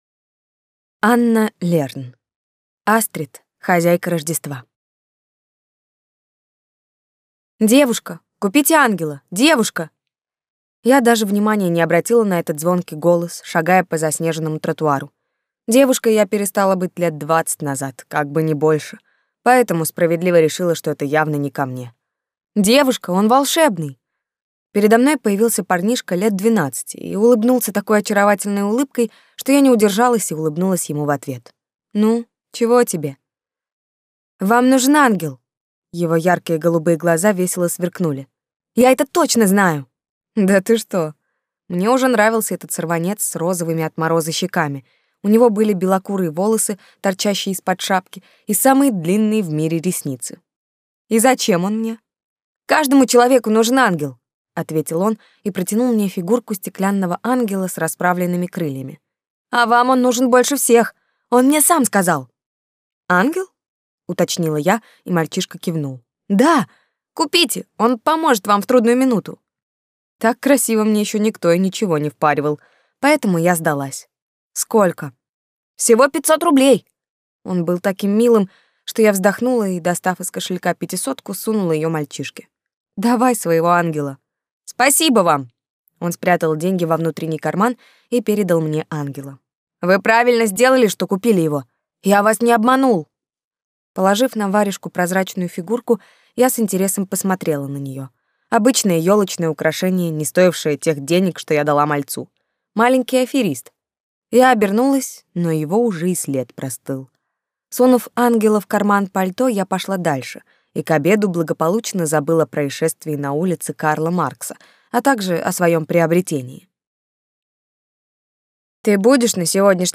Аудиокнига Астрид – хозяйка Рождества | Библиотека аудиокниг